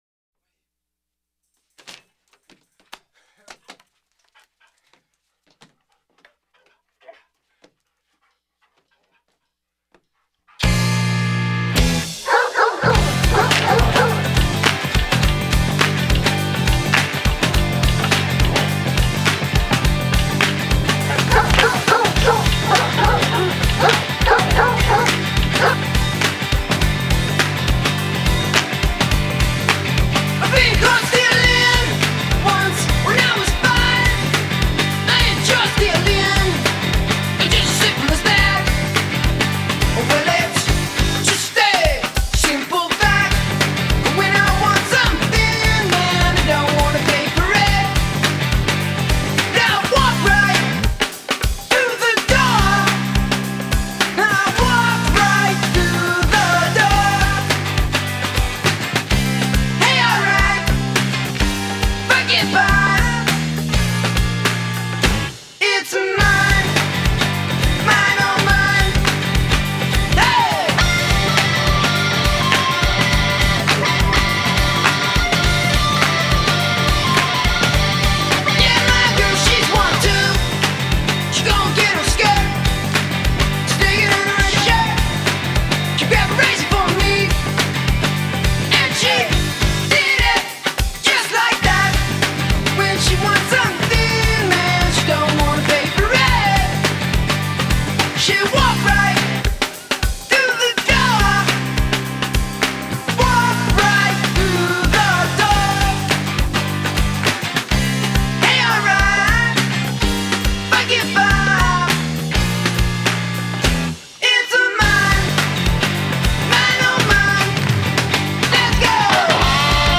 helium nasal whine